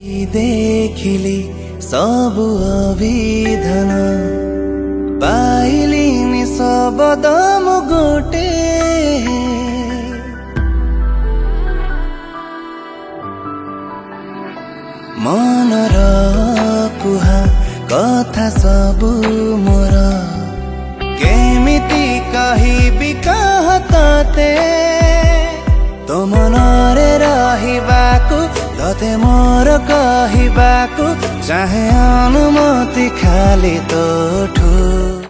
Romantic song